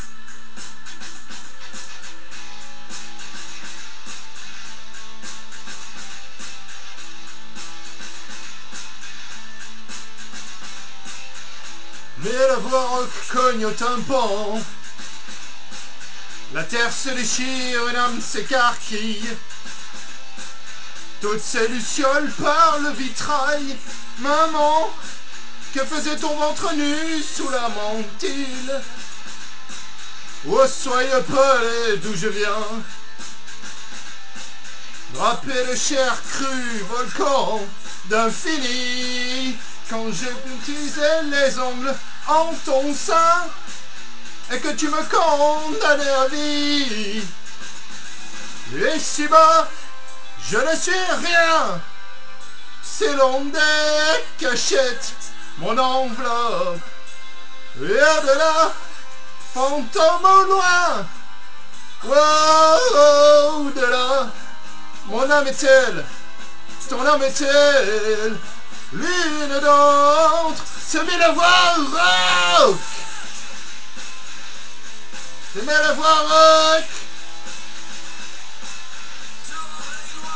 En plus le micro est pourrit :